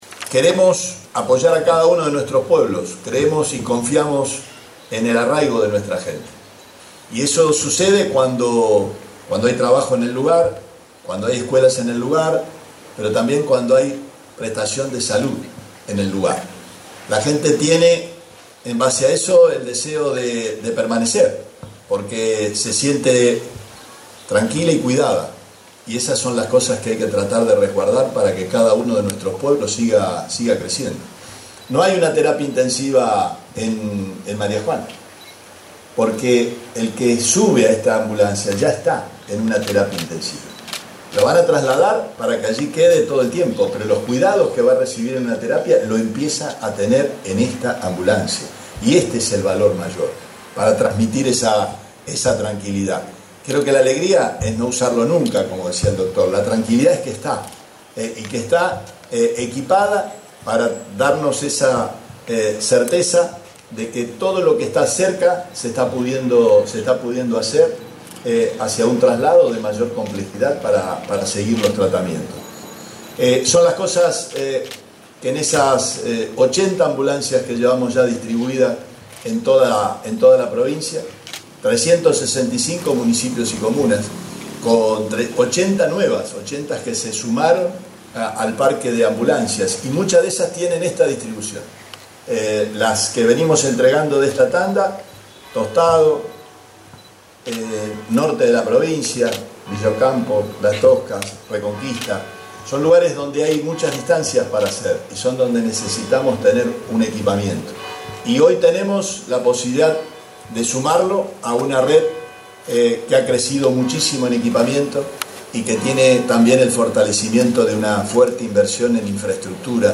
El gobernador de la provincia, Omar Perotti, entregó este lunes una ambulancia a la Clínica Comunal Santa Juana, de la localidad de María Juana, en el departamento Castellanos.
Declaraciones Perotti